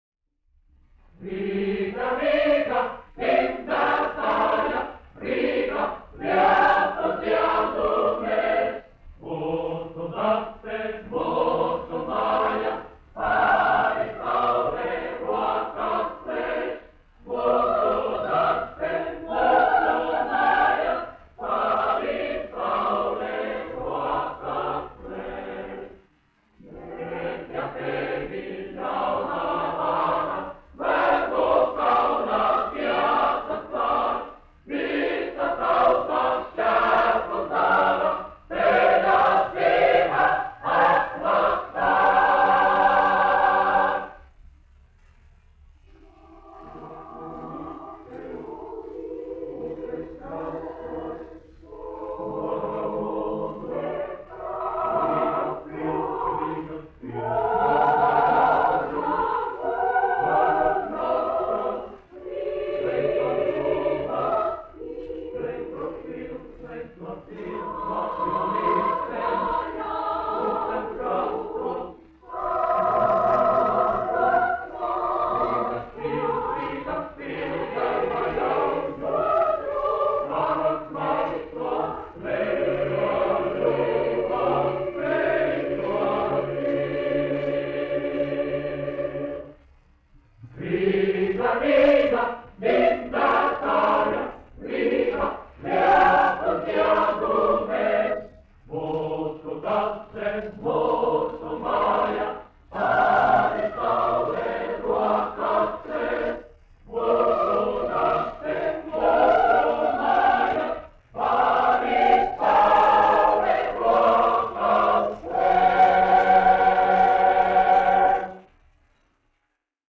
Latvijas Radio Teodora Kalniņa koris, izpildītājs
Kalniņš, Teodors, 1890-1962, diriģents
1 skpl. : analogs, 78 apgr/min, mono ; 25 cm
Kori (jauktie)
Skaņuplate